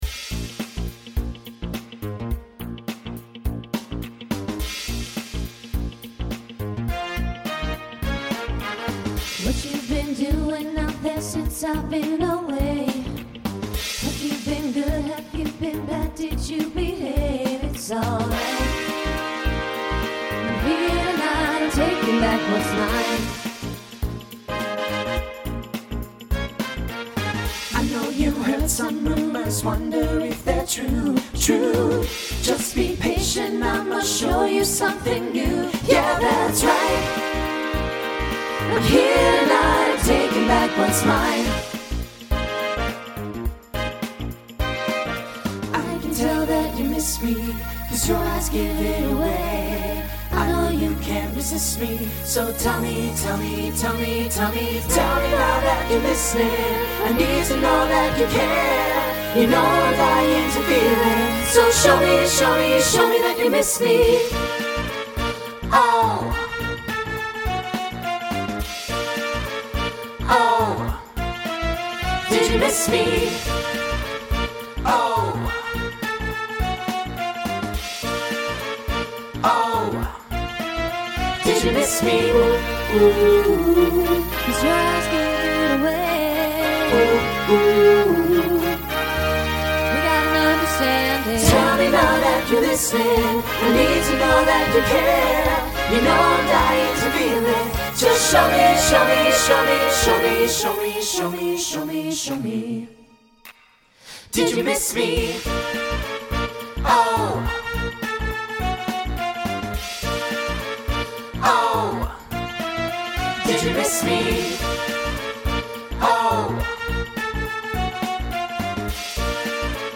SATB SSA